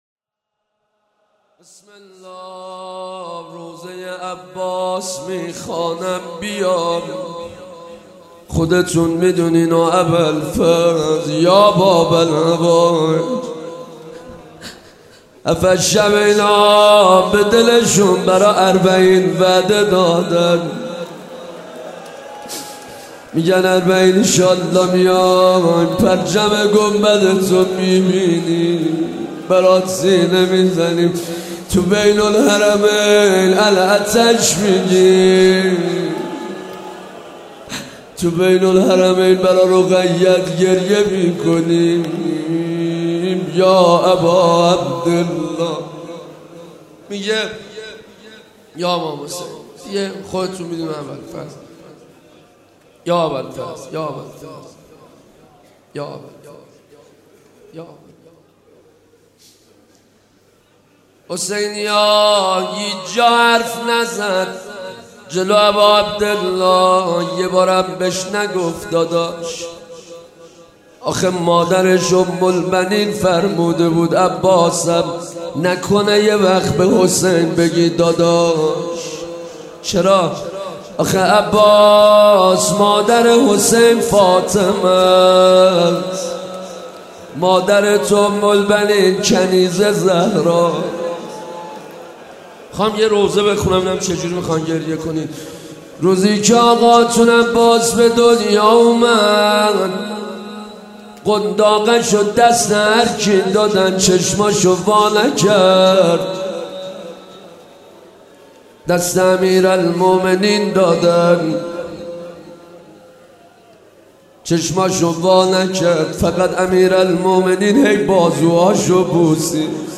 روضه حضرت عباس